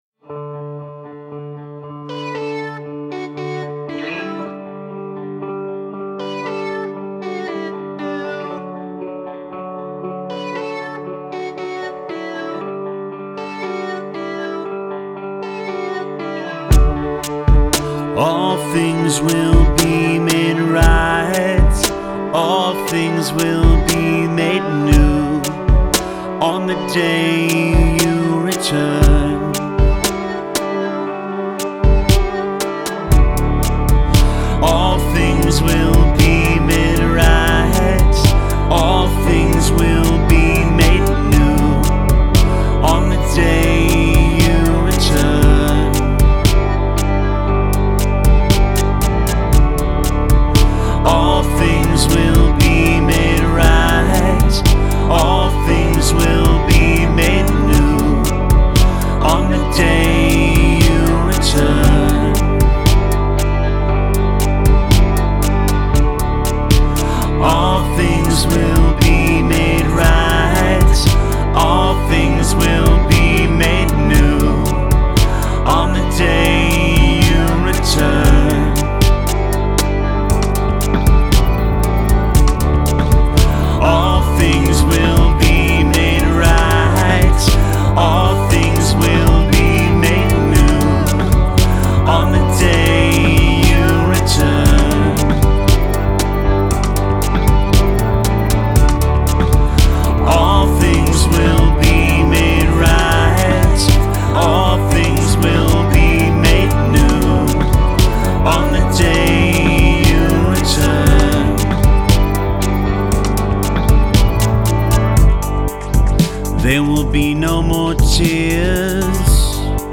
indie-emo-rock band
American indie rock band
vocals, guitar
bass
drums